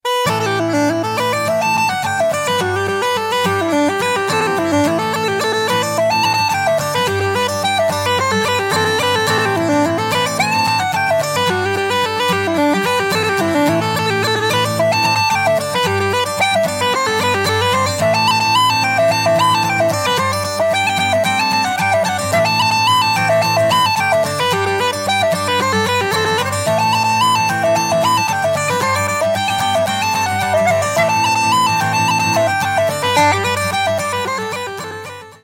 Fiddle
Bodhran
a lyrical hornpipe